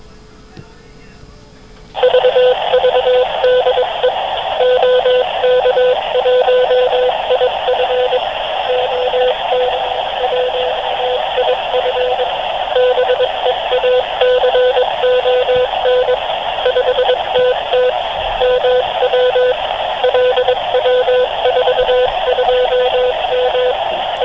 V neděli jsem tedy nechal puštěn maják a odjel jsem na druhou stranu Ještěda, do Hamru na jezeře.
Na jedné straně tedy Rockmite s 500 mW a na druhé straně FT817 s dipolem 2x9m přes balun.
Zvuk maják (*.wma 534 kB)
majak_Hamr.wav